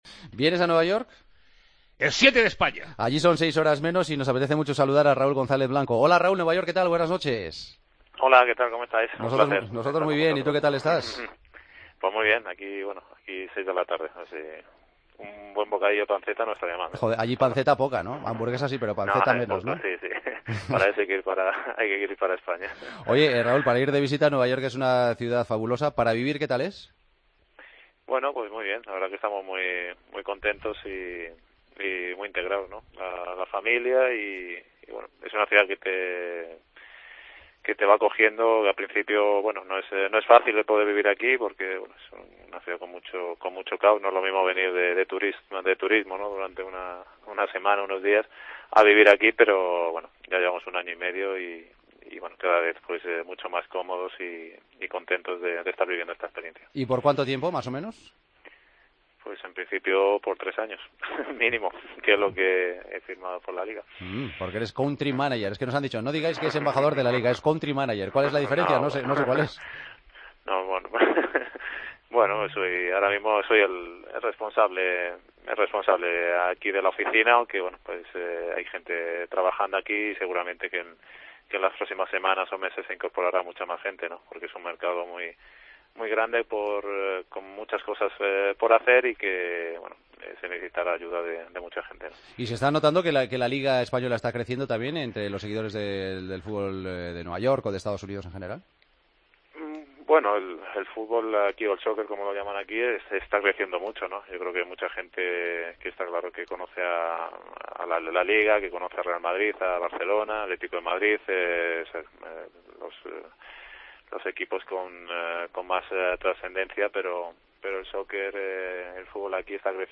AUDIO: Raúl González atendió desde Nueva York la llamada de El Partido de las 12, en la previa de un Manchester City - Real Madrid: "Echo...